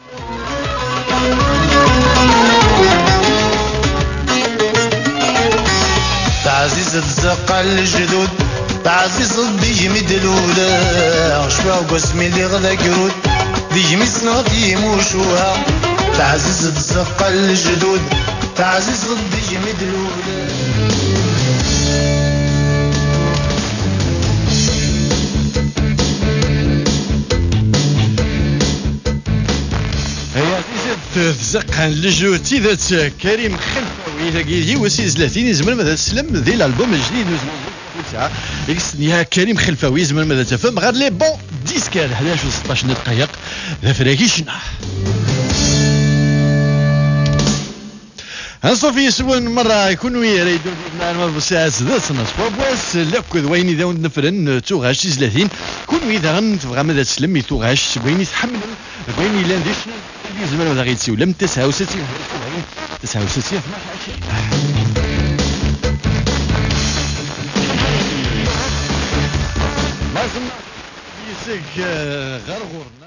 This needs douple-hop for sure (3000 km).
NICE SIGNAL: